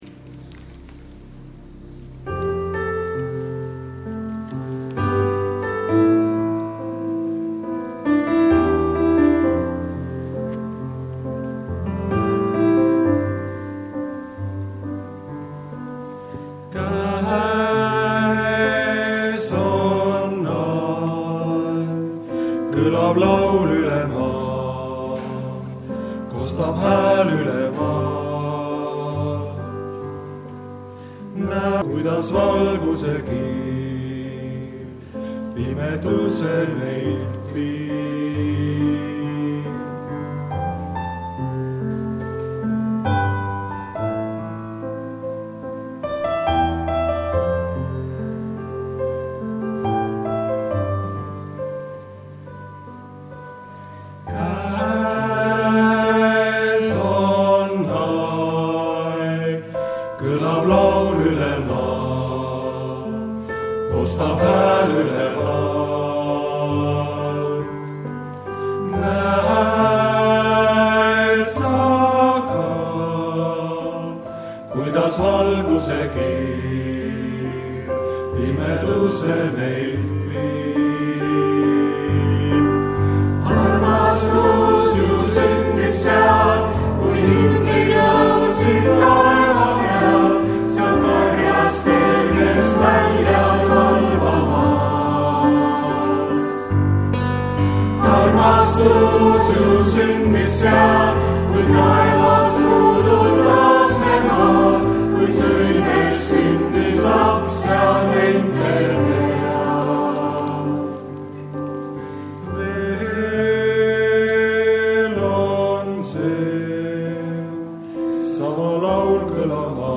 Muusika: Koguduse jõulukoor Kestus